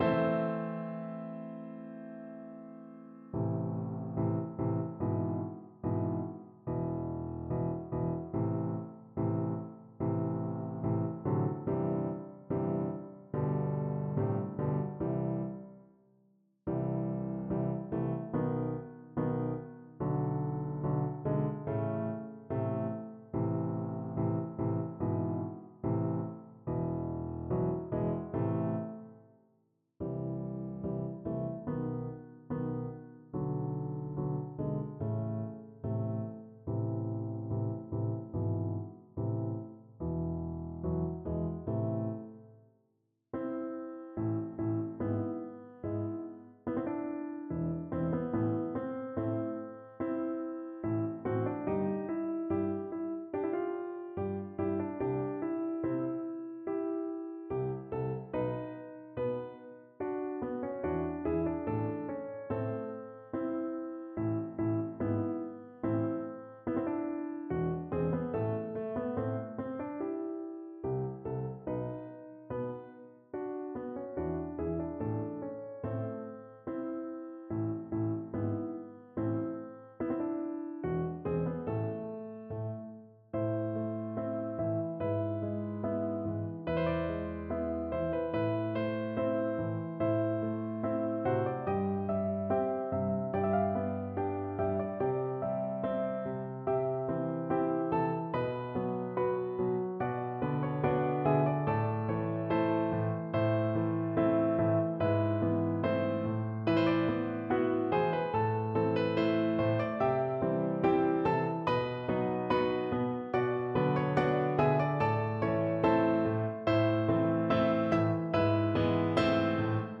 (in A major)